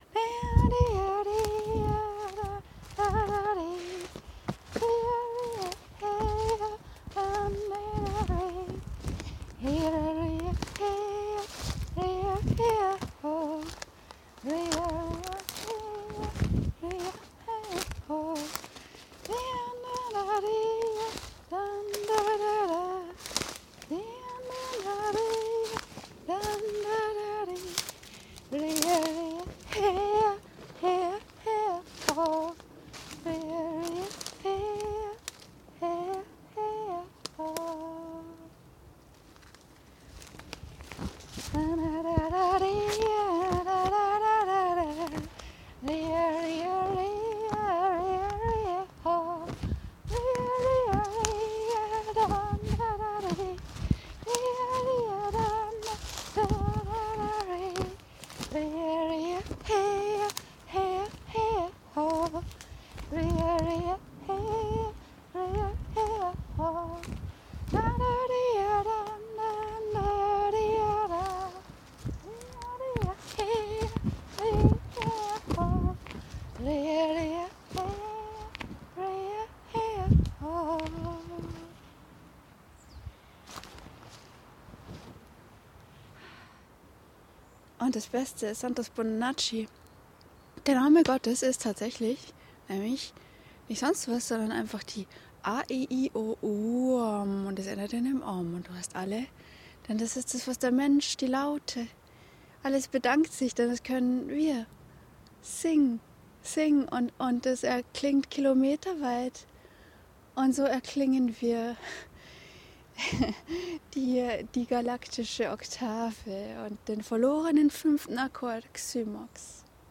singen
singen.mp3